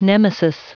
Prononciation du mot nemesis en anglais (fichier audio)
Prononciation du mot : nemesis